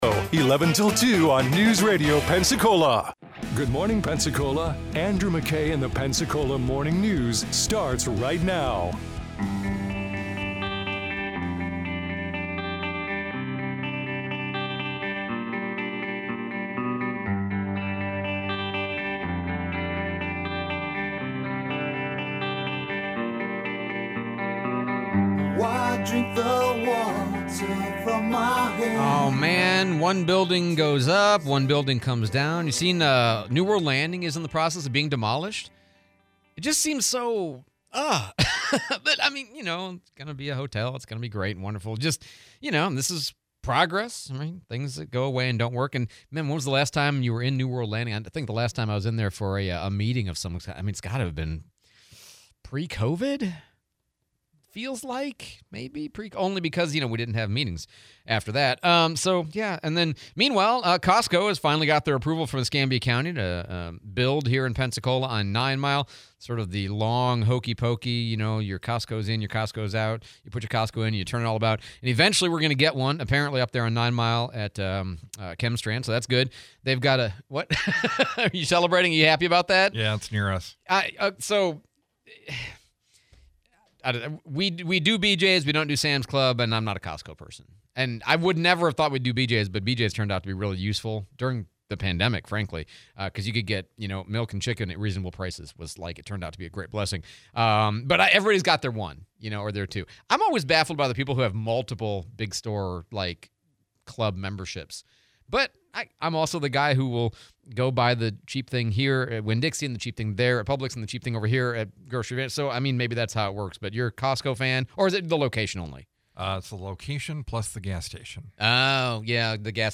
Testing for teachers, interview